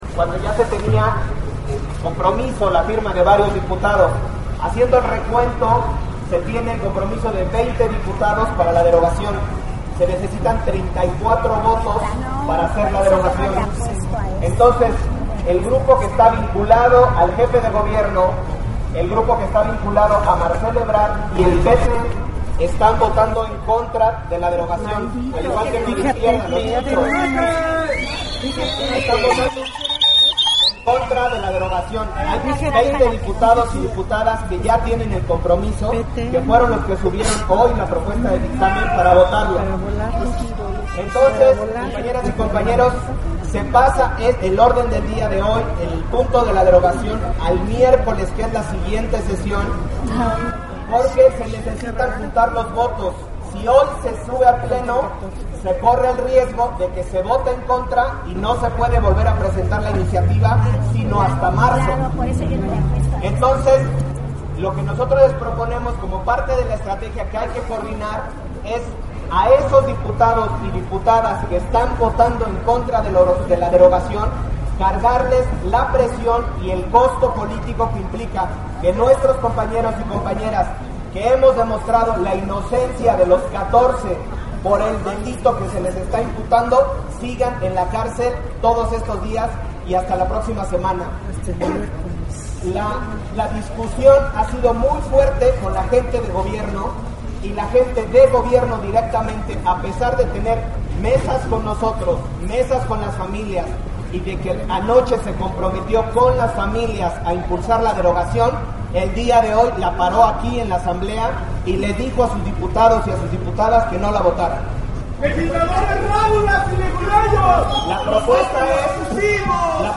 Cacerolazo en la Asamblea Legislativa del Distrito Federal: Derogación al artículo 362.
Participación de la liga de abogados 1DMX
Gran parte de los manifestantes  portaban cacerolas, cucharones, botellas, cubetas, entre otros objetos para hacer ruido.